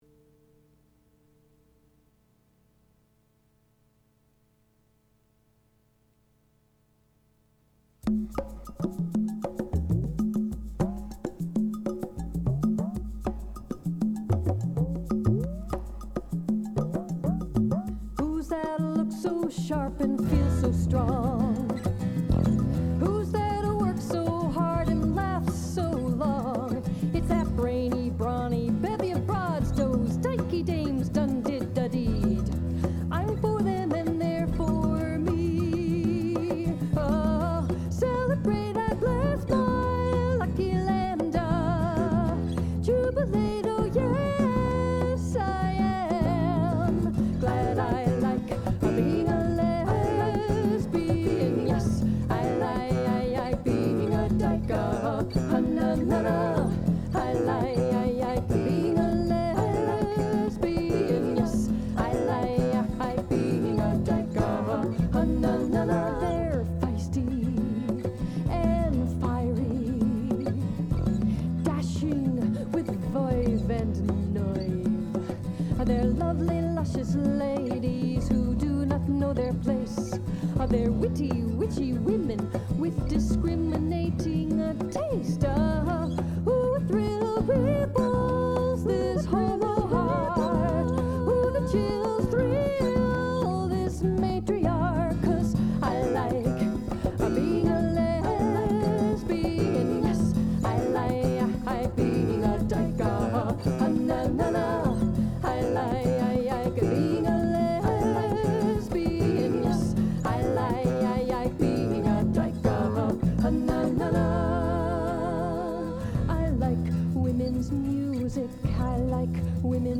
Each week, the show would open with a theme song.